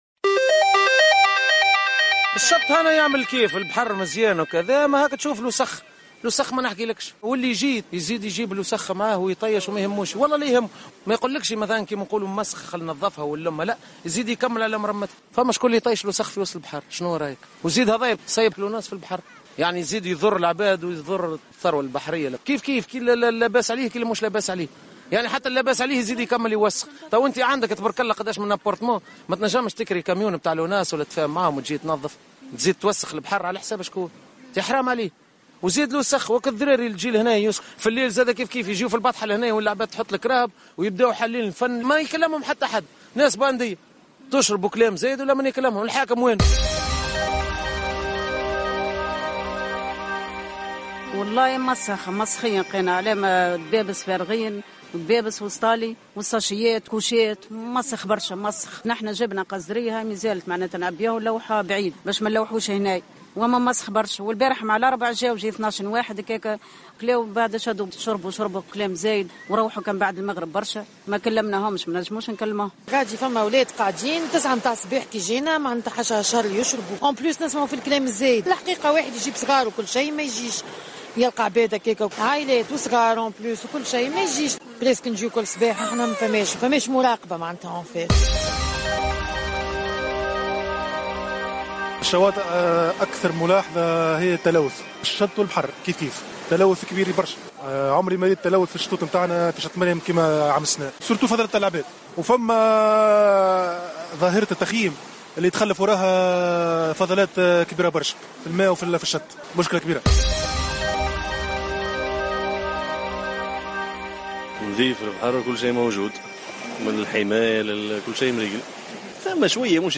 وتحدث عدد من المصطافين في منطقة شط مريم لميكرو جوهرة أف أم، عن وضعية الشاطئ خلال هذه الصائفة مجمعين على انعدام الخدمات البلدية.
رأي المصطافين في وضعية الشواطئ